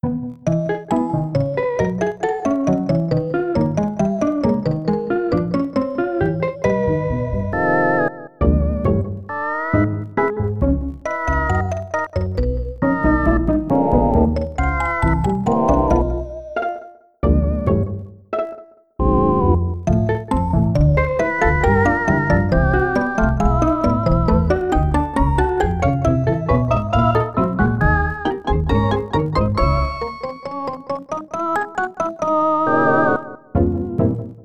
EXAMPLE 2: SNESMOD (improperly tracked):
This example showcases SNESMOD's glaring "pop noise" issue, which most frequently occurs when new notes trigger in a channel where a previous note was playing.
In this example, I removed all the SCx commands in my song.